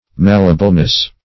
Malleableness \Mal"le*a*ble*ness\, n.